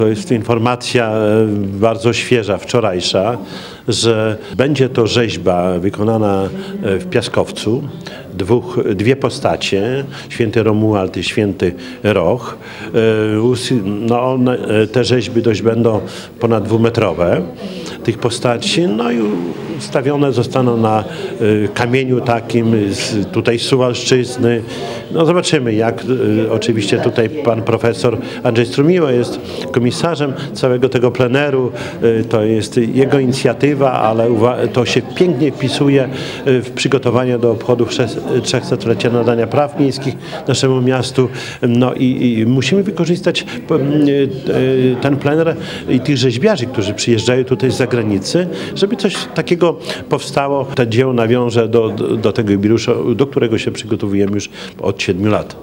O szczegółach mówi Czesław Renkiewicz, prezydent miasta.